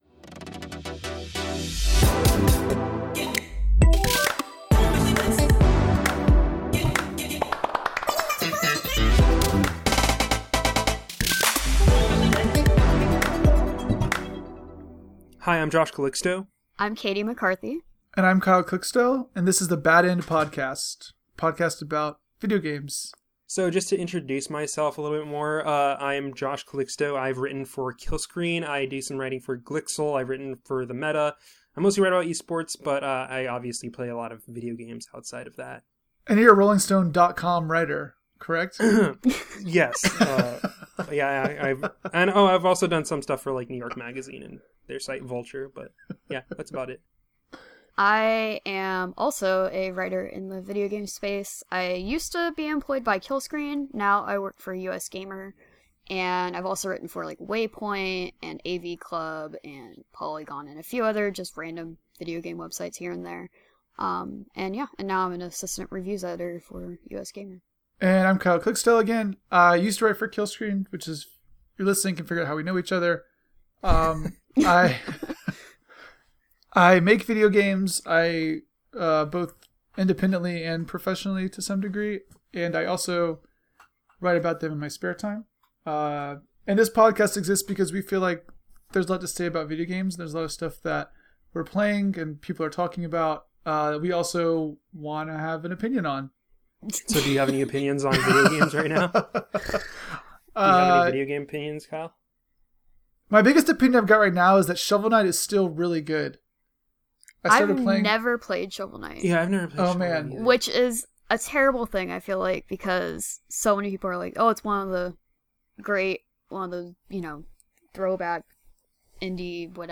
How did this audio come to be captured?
Beschreibung vor 8 Jahren We're live with the first episode of BAD END! Join us as we talk about the Nintendo Switch, Nier: Automata, Shovel Knight, and of course, The Legend of Zelda: Breath of the Wild.